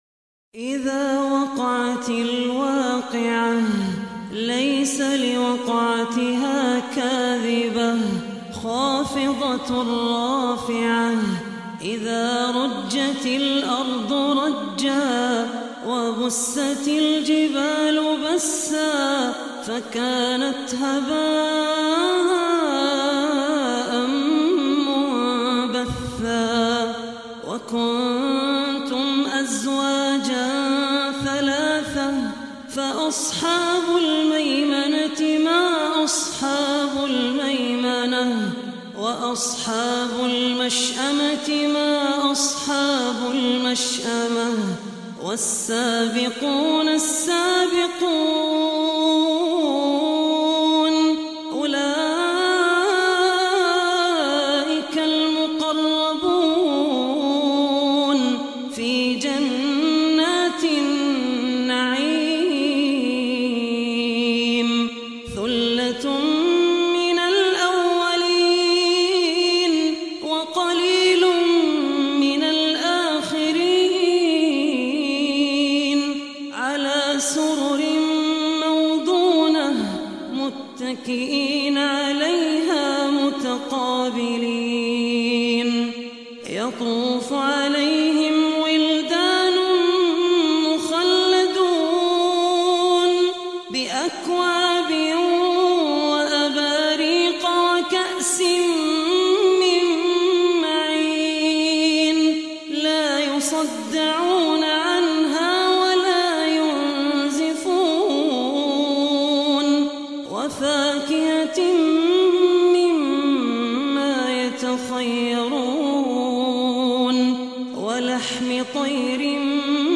Surah Waqiah Recitation by Abdur Rahman Al Ossi
Surah Waqiah, listen online mp3 tilawat / recitation in Arabic in the voice of Sheikh Abdur Rahman Al Ossi.
surah-waqiah.mp3